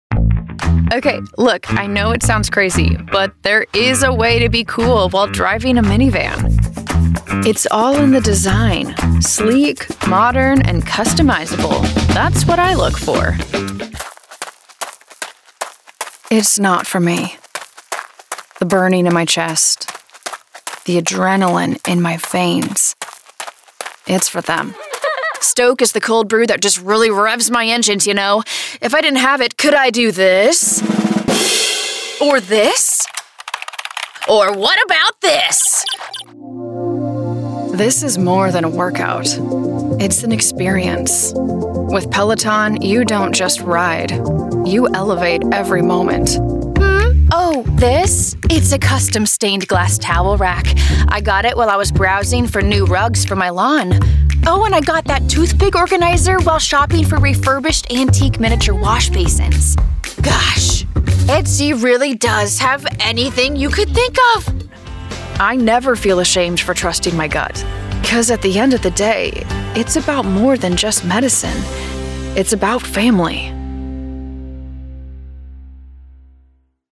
Natural, Urbana, Cálida
Comercial